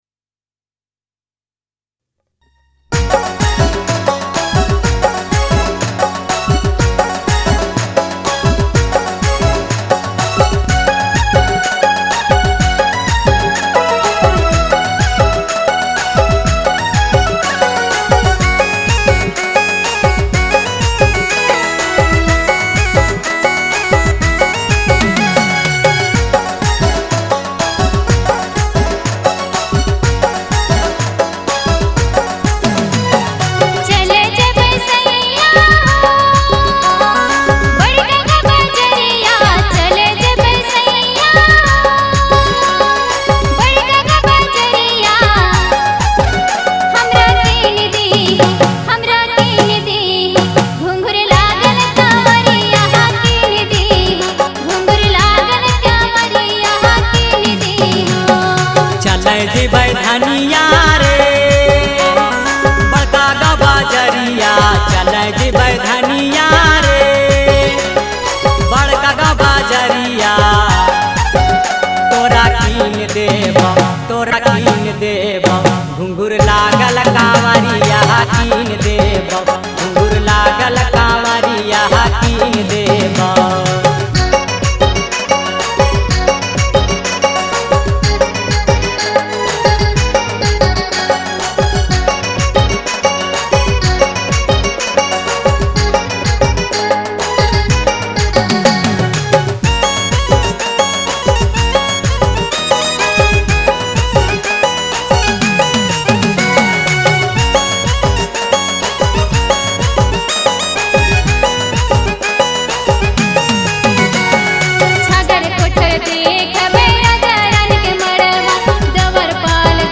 Nagpuri song